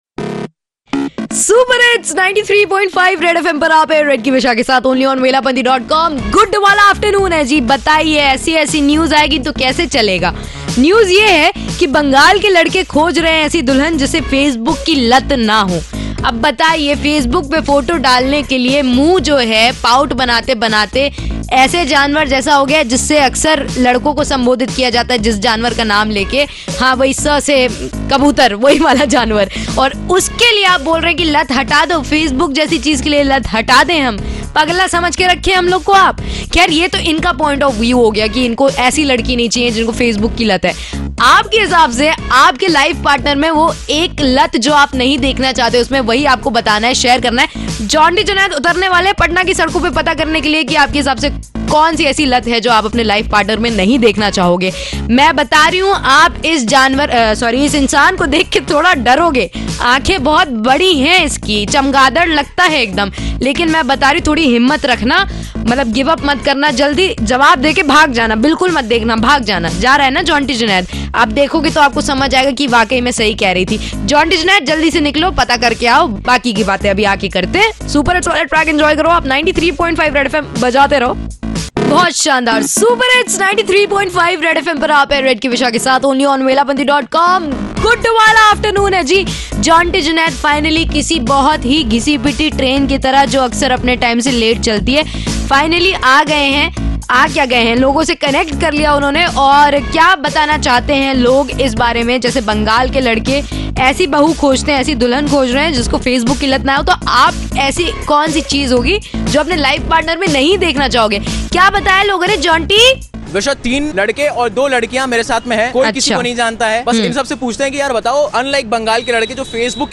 Vox-POP